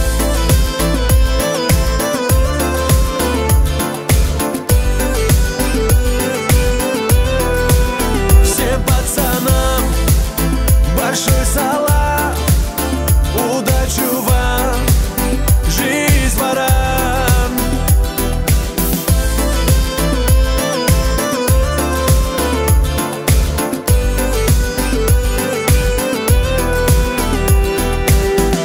• Качество: 320, Stereo
мужской голос
спокойные
русский шансон